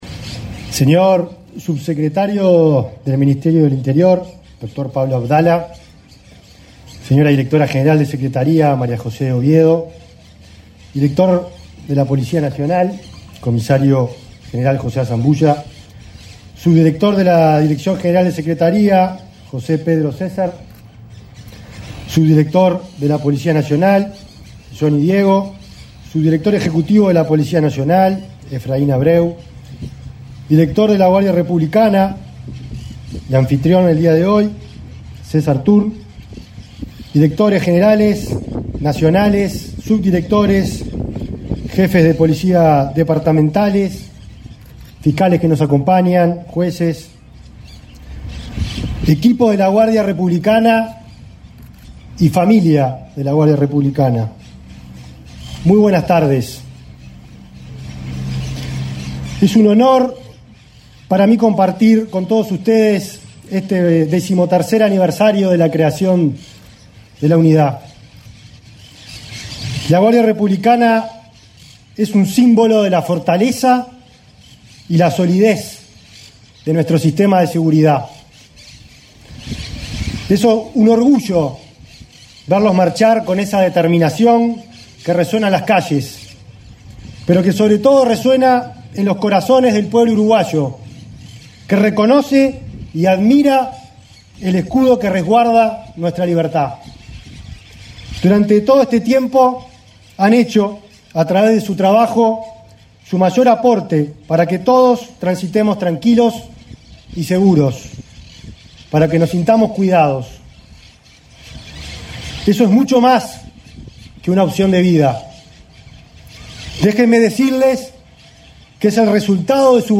Palabras del ministro del Interior, Nicolás Martinelli
El ministro del Interior, Nicolás Martinelli, participó del acto por el 13.° aniversario Guardia Republicana, realizado este 27 de diciembre.